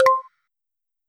voice-click-mp.mp3